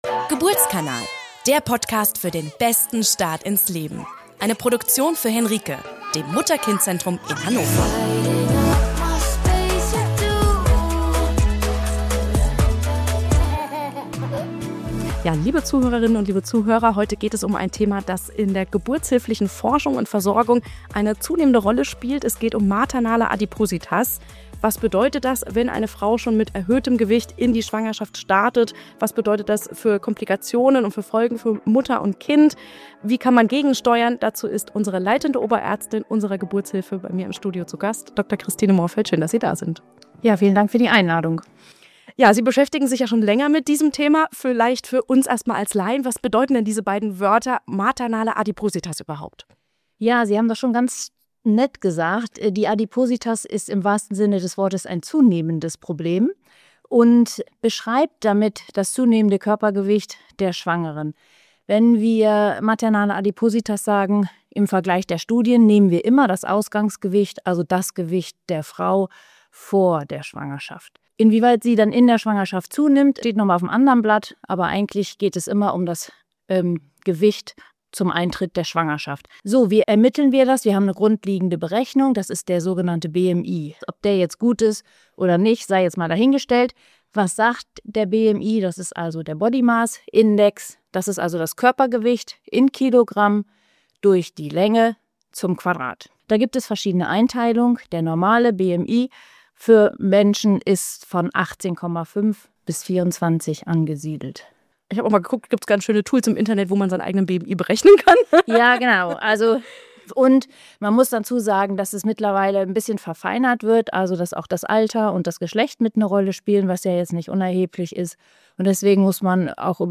Chancen, Risiken, Unterstützung, ein Gespräch